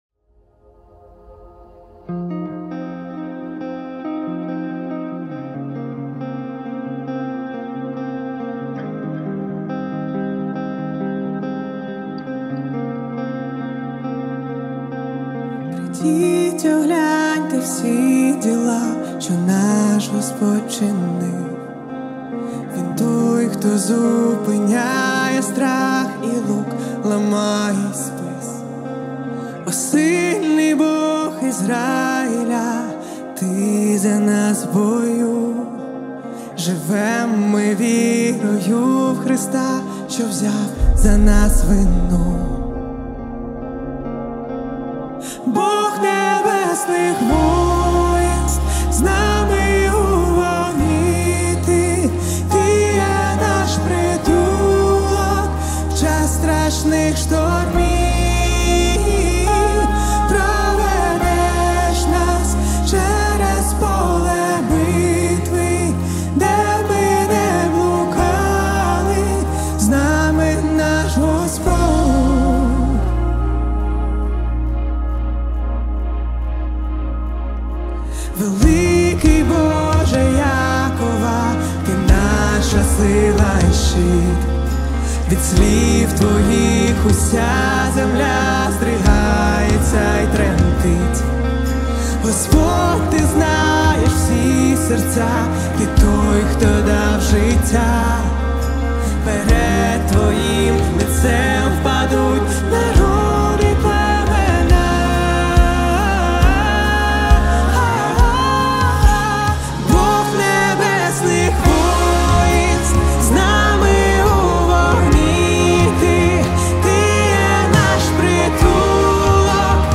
326 просмотров 198 прослушиваний 24 скачивания BPM: 69